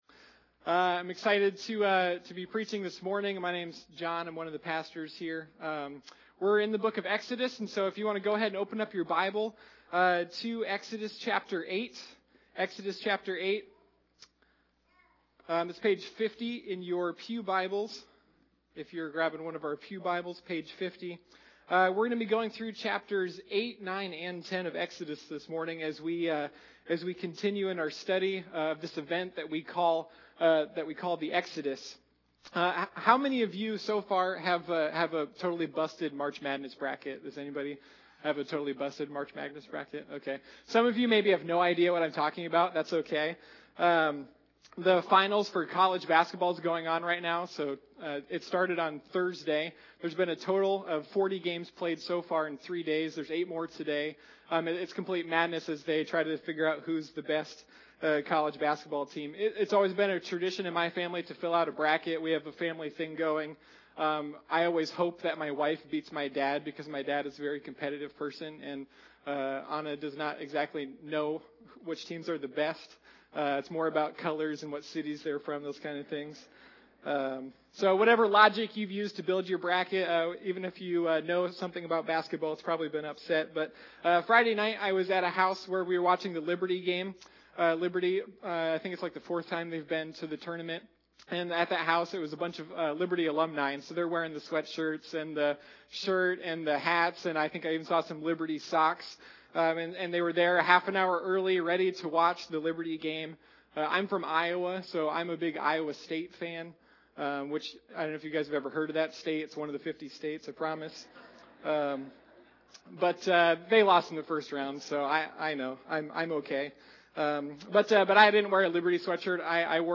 Sunday Morning Rescue: A Study of the Exodus